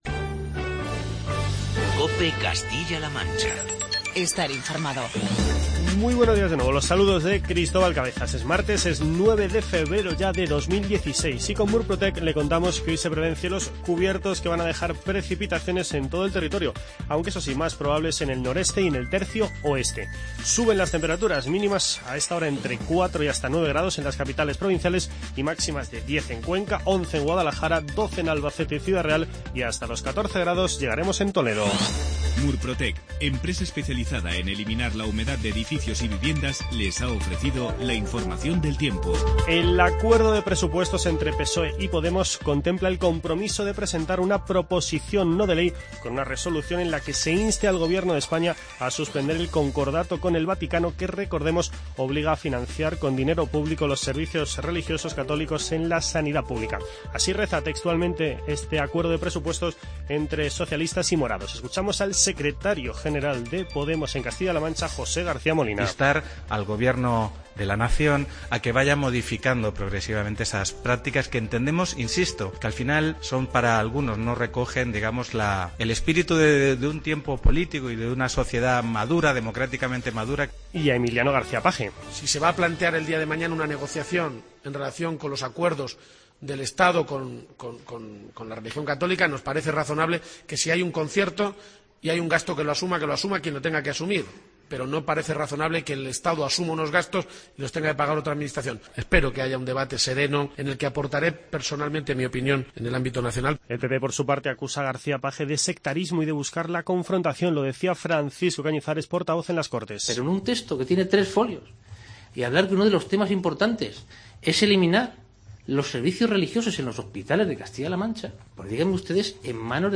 Informativo regional
Escuchamos las palabras de José García Molina, Emiliano García-Page y Francisco Cañizares, entre otros protagonistas.